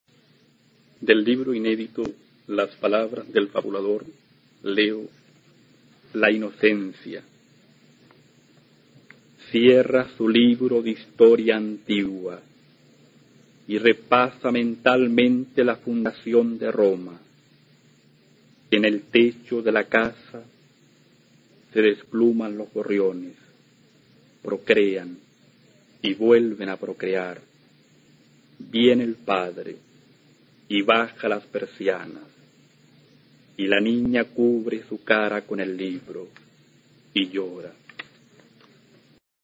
A continuación se puede escuchar a Jaime Quezada, autor chileno de la Generación del 60, recitando su poema La inocencia, del libro "Las palabras del fabulador" (1968).
Poema